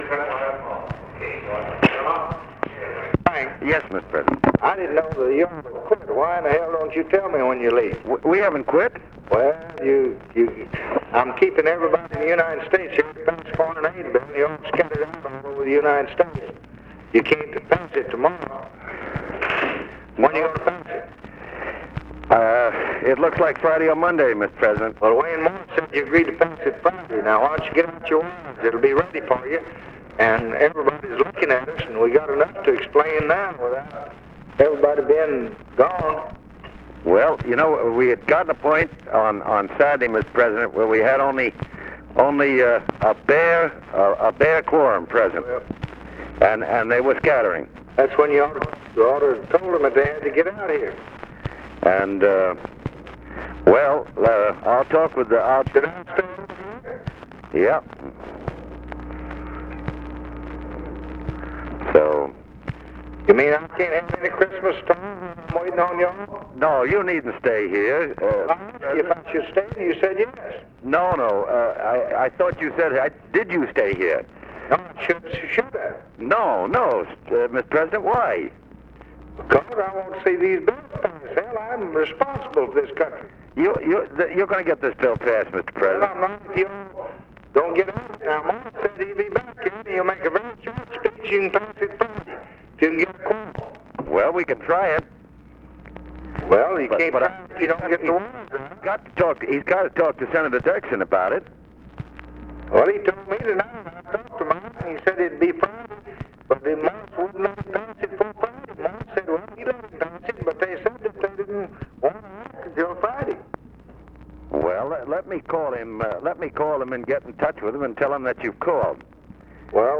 Conversation with FRANK VALEO, December 23, 1963
Secret White House Tapes